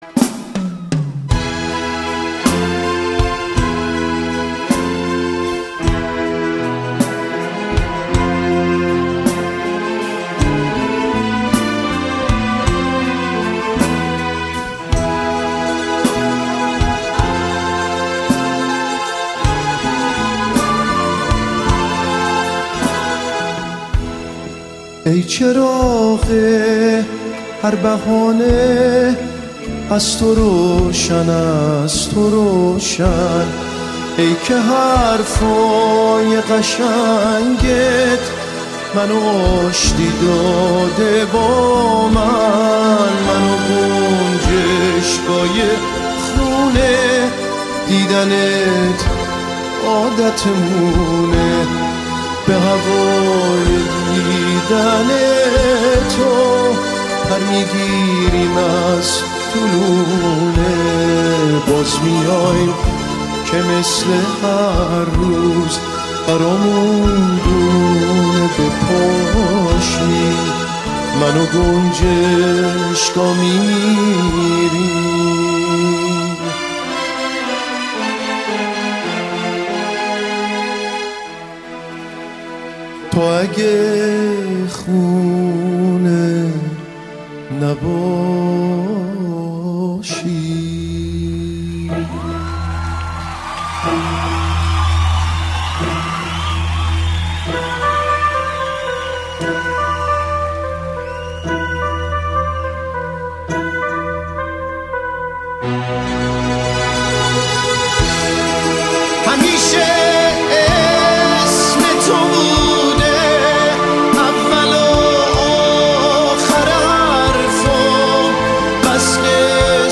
کنسرت سانتامونیکایِ